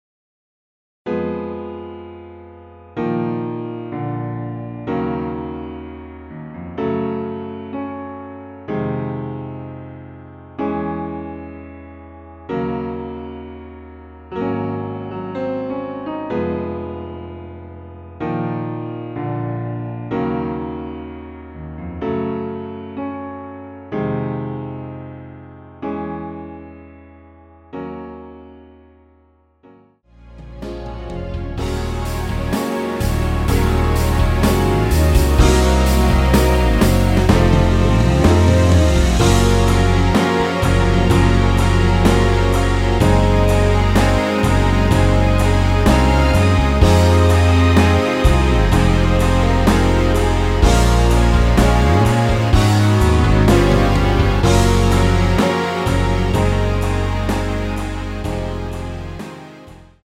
Db
앞부분30초, 뒷부분30초씩 편집해서 올려 드리고 있습니다.